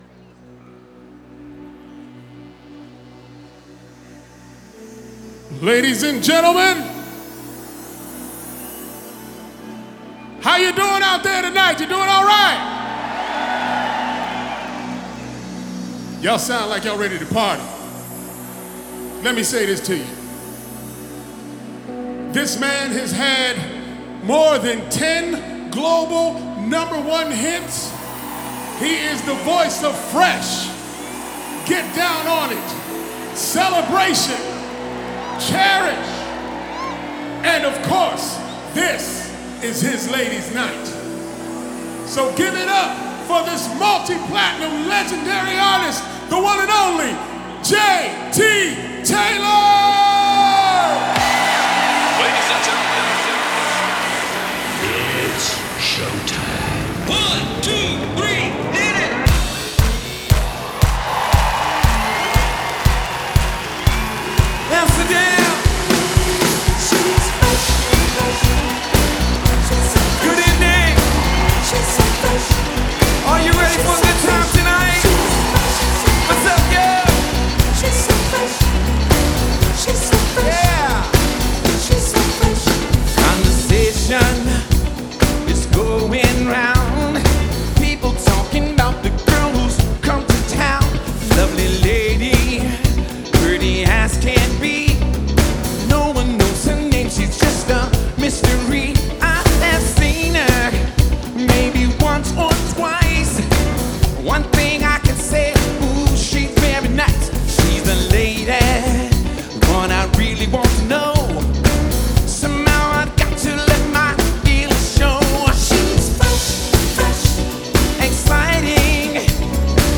Genre: Soul.